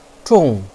zhong4.wav